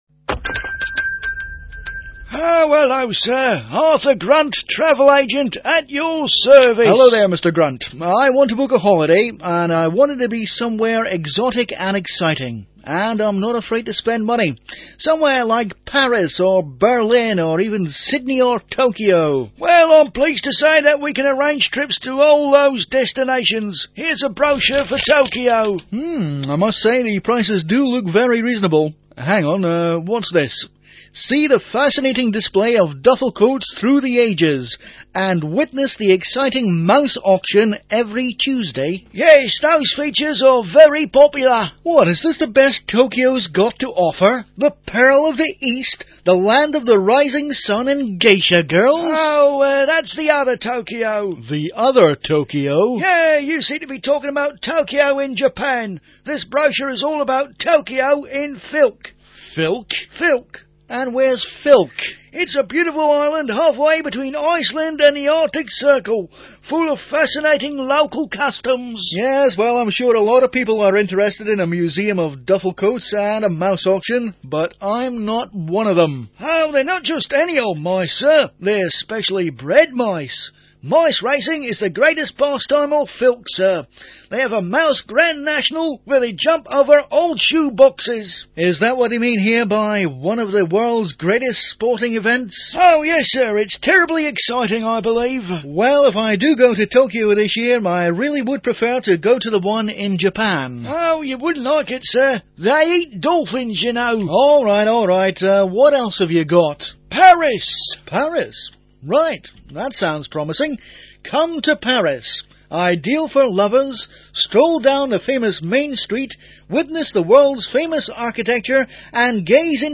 Comedy Sketches